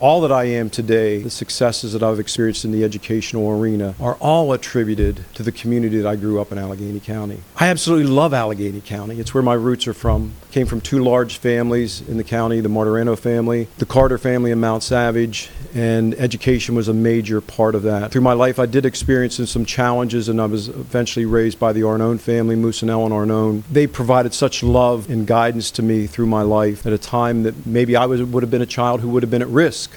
Dr. Martirano addressed the board briefly after the announcement…